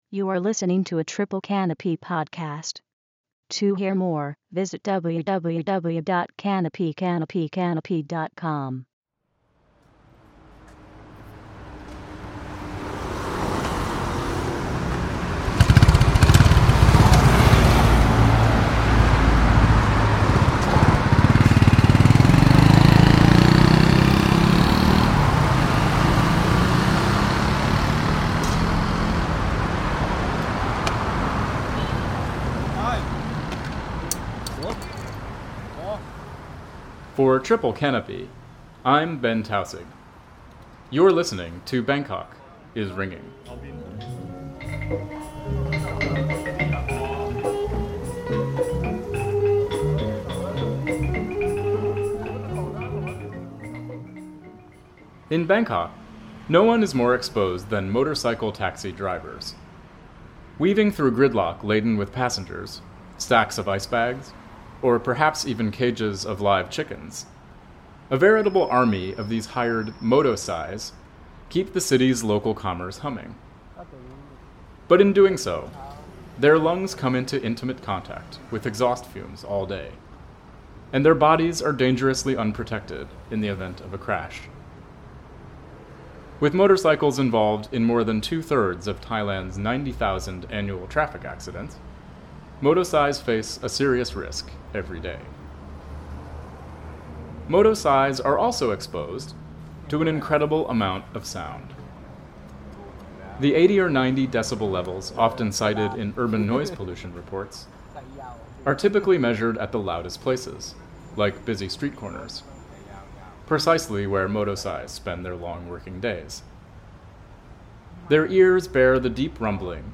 This episode describes twelve hours in the auditory lives of Bangkok's motosai, the motorcycle taxi drivers known as the ears of the street. They absorb the city's noise and rumor, from the roar of traffic to the gossip of street vendors, long workday after long workday.
Published on May 4, 2011 Download -:-- / -:-- A series exploring the politics of urban sound in Bangkok and beyond, through first-person reporting, field recordings, and analysis.